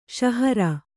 ♪ ṣahara